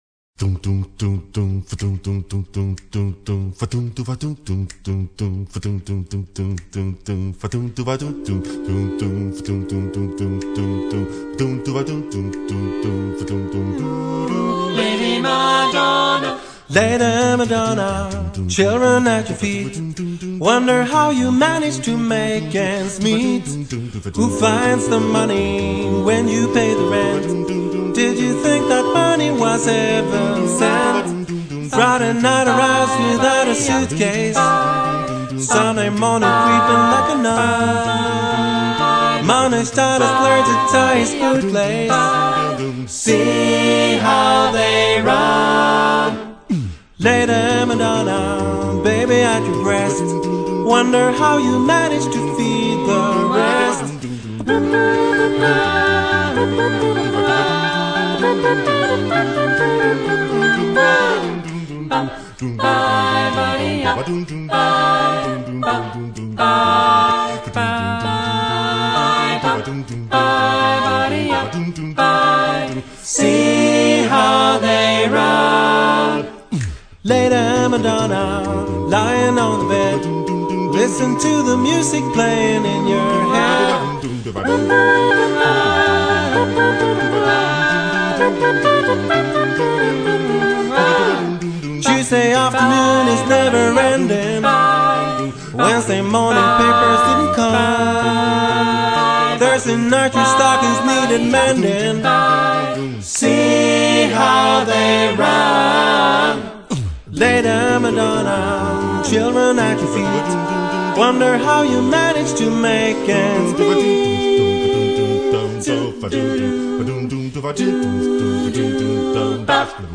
Ljuva 60-tal - acappella